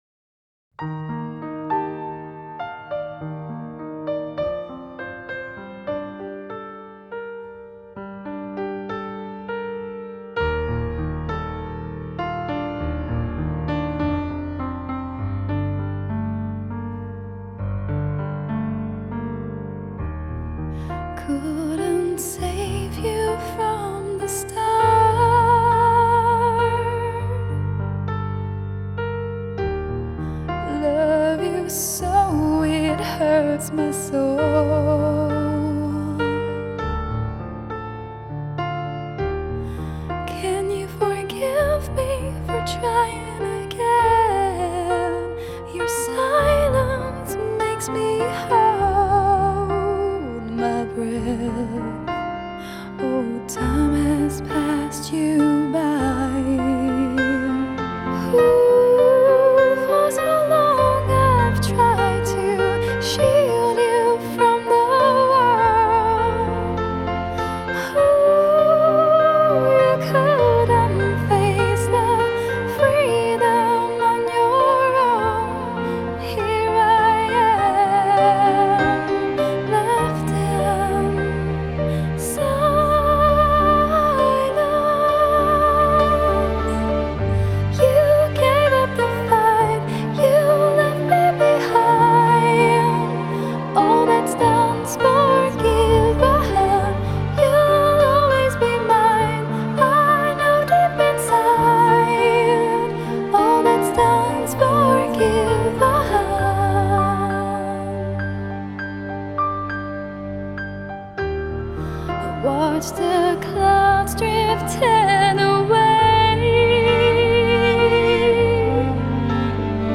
Genre : Gothic Rock